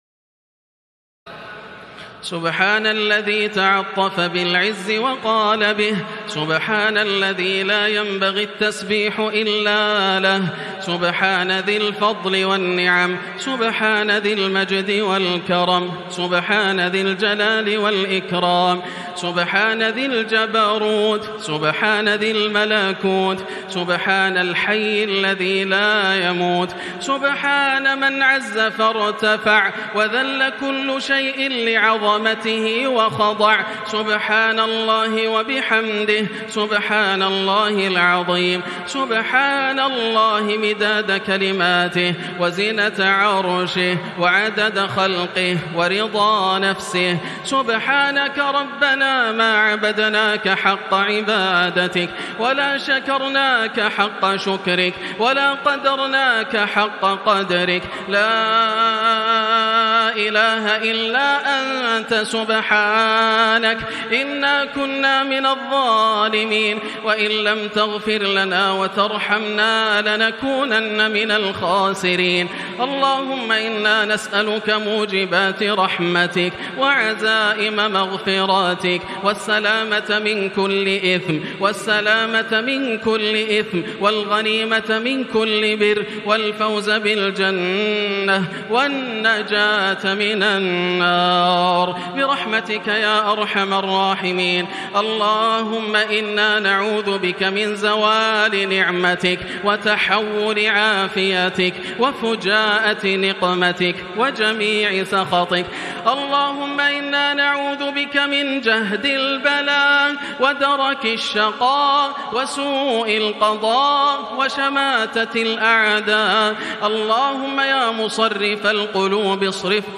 دعاء القنوت ليلة 18 رمضان 1440هـ | Dua for the night of 18 Ramadan 1440H > تراويح الحرم المكي عام 1440 🕋 > التراويح - تلاوات الحرمين